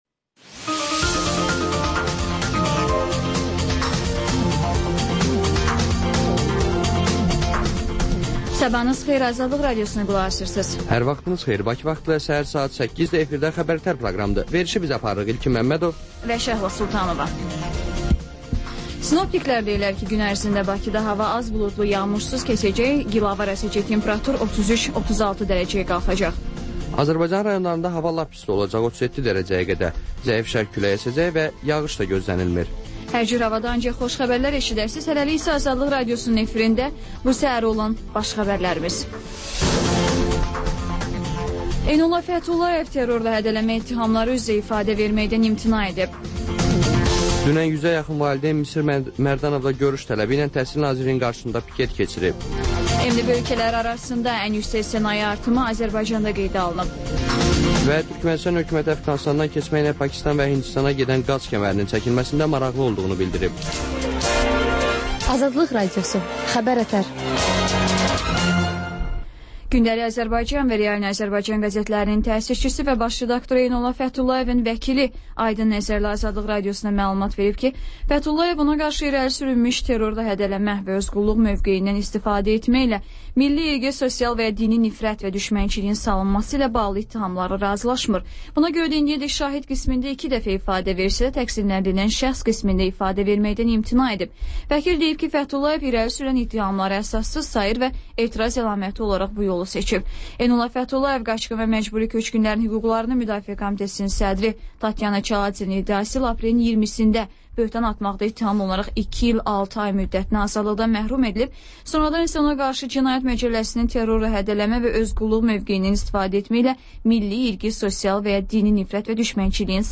Xəbərlər, müsahibələr